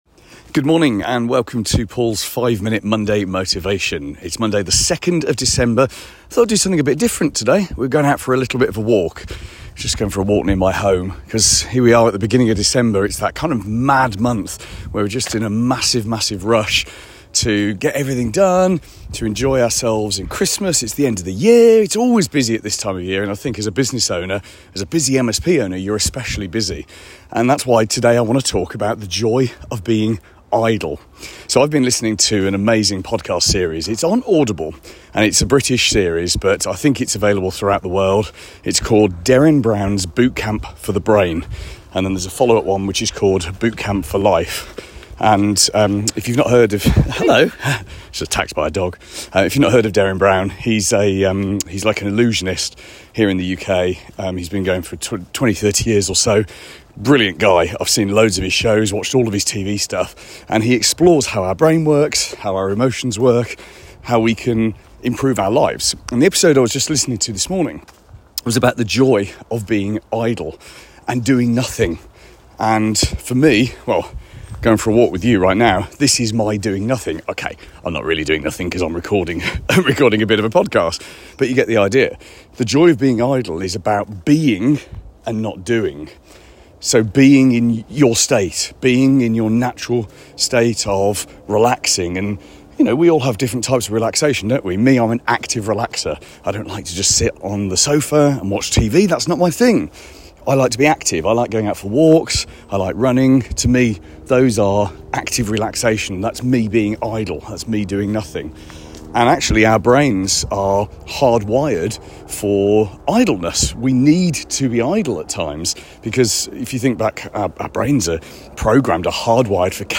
I'm taking you out for a pleasant winter walk this week. Let's talk about an inspirational podcast I've been listening to, and how it's encouraged me to be more idle.